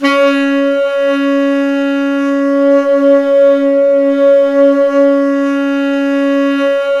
SAX_smc#4bx   25.wav